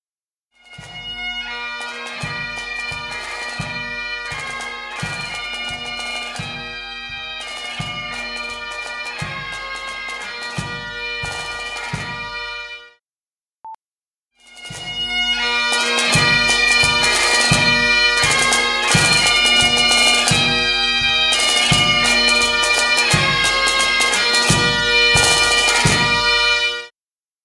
I am recording our bagpipe band for a CD. We are practicing now We are using a Zoom R16 to record 8 tracks.
Here’s sentence I’d never thought I’d write : the bagpipes aren’t loud-enough …
Stereo mostly lives above 6kHz , your recording benefits from boosting that frequency range IMO …